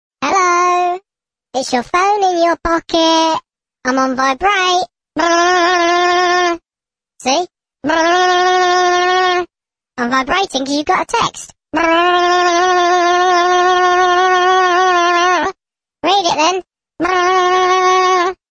Vibrating Phone